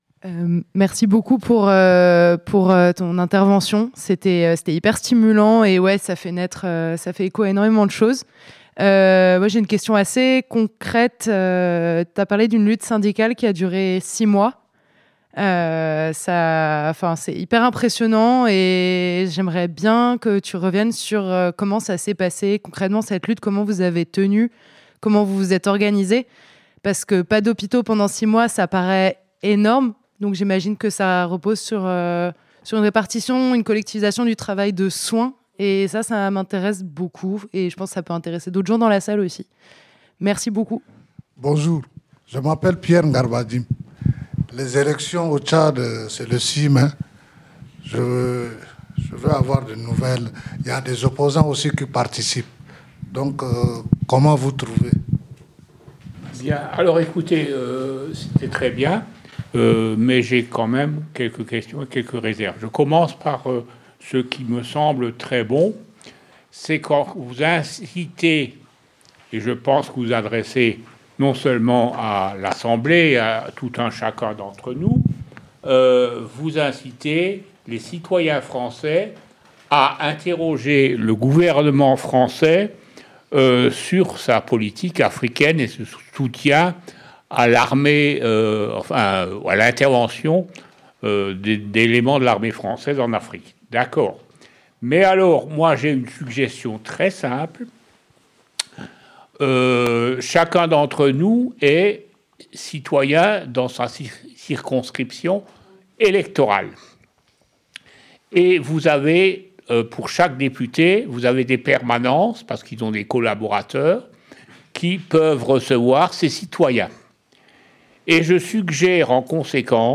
Cet événement s’inscrit dans la série de nos soirées internationalistes, des espaces qui visent à se former et à s’organiser pour mieux lutter contre tous les impérialismes.
Questions-réponses